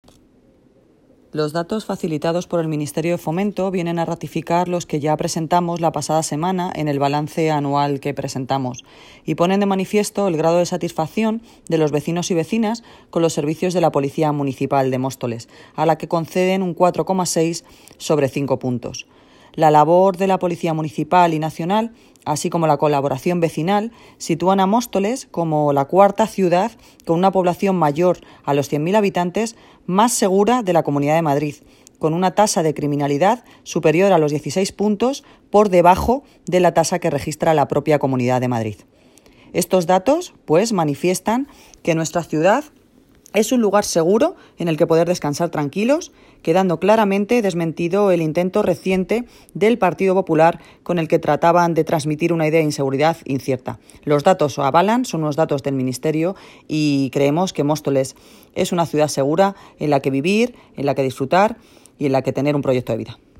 Audio - Noelia Posse (Alcaldesa de Móstoles) Sobre los buenos datos de Móstoles respecto al índice de criminalidad